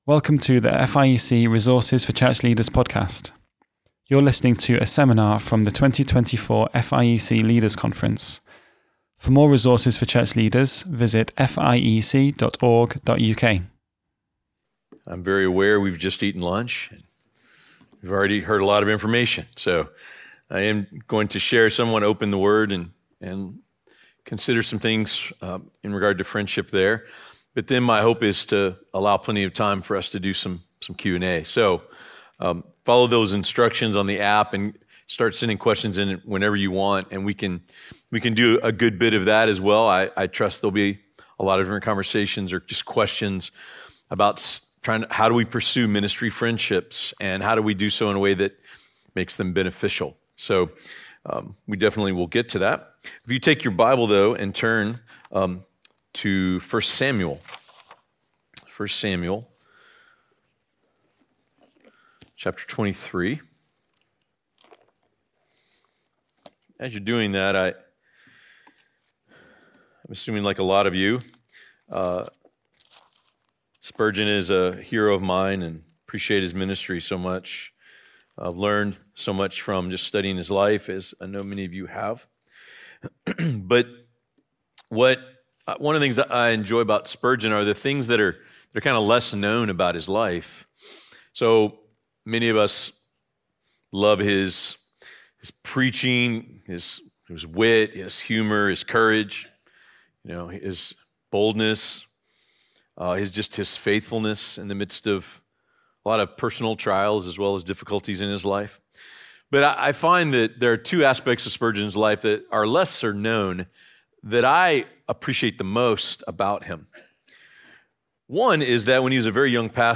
Series: Leaders' Conference 2024